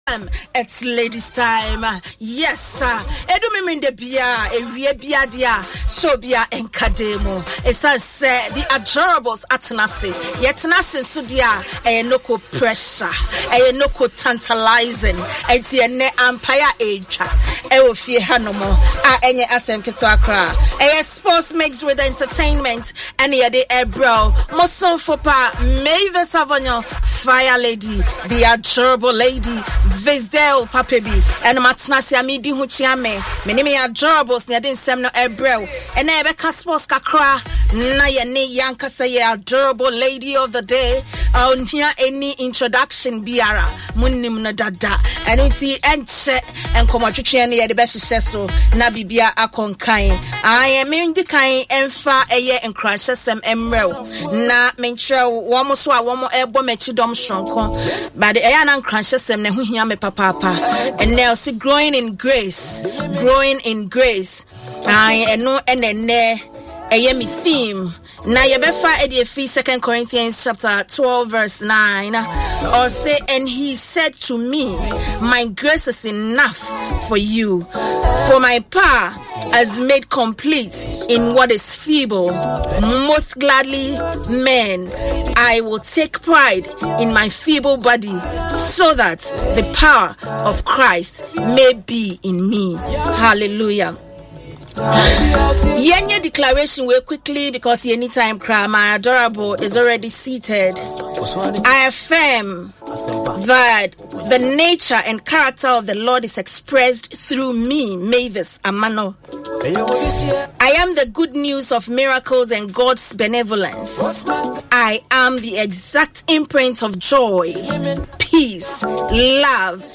A fun-filled discussion of sports and sporting issues by female celebrities.